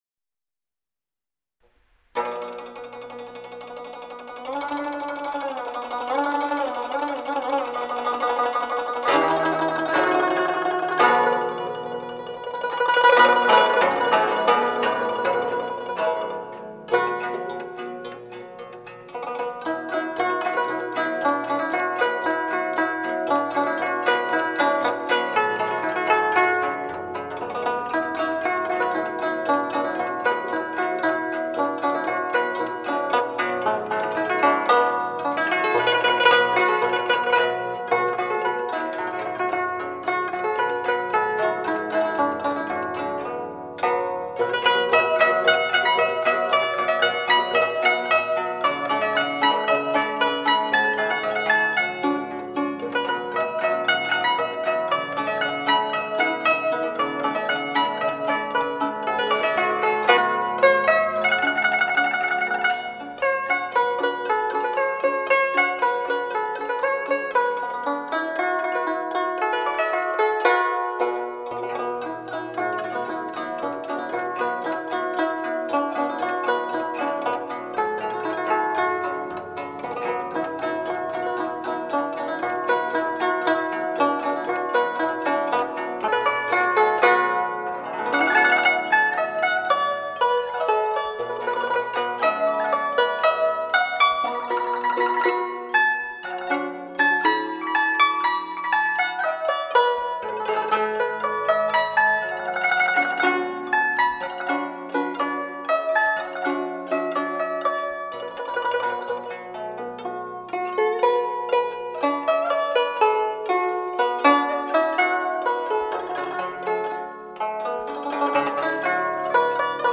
古筝的古朴典雅、恬静柔美，让人荡气回肠，回味无穷。